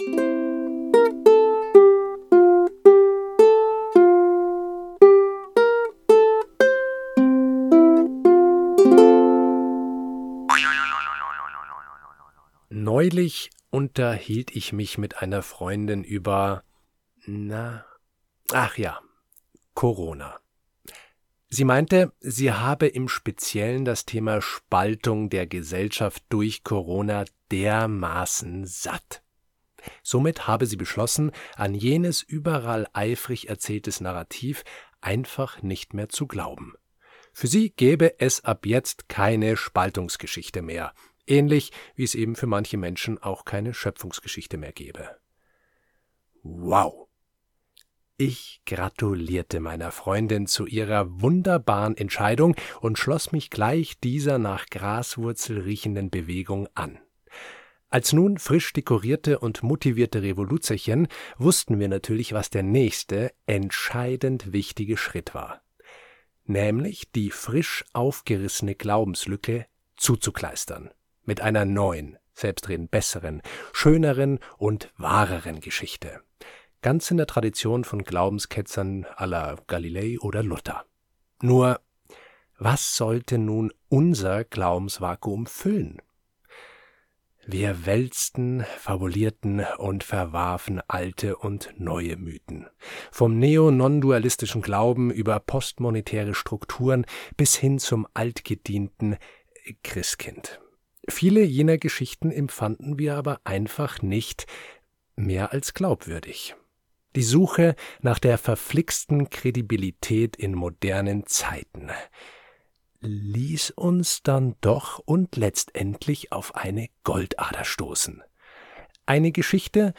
Freitagskolumne zum Hören